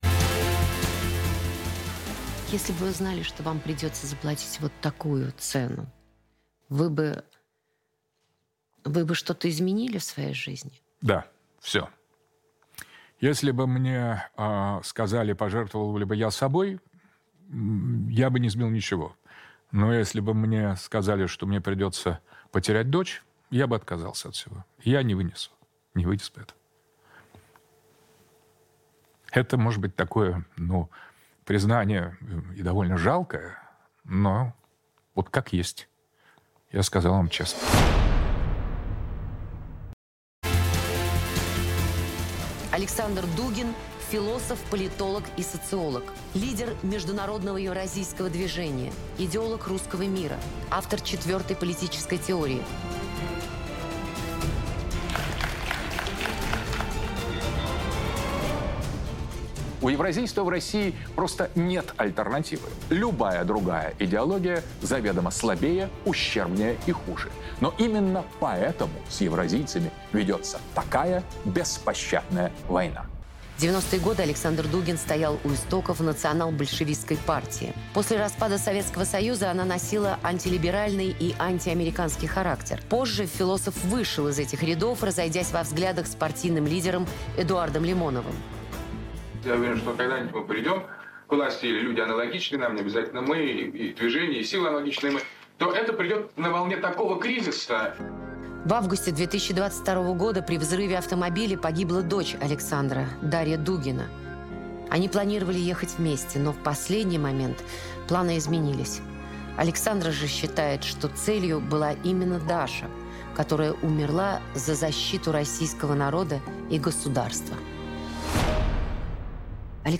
Эксклюзивное интервью Александра Дугина. Время покажет.